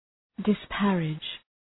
Shkrimi fonetik {dı’spærıdʒ}